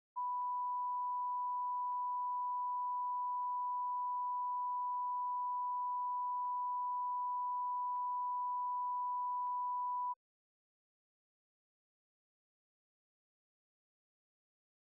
Conversation: 227-006
Recording Device: Camp David Hard Wire
The Camp David Hard Wire taping system captured this recording, which is known as Conversation 227-006 of the White House Tapes.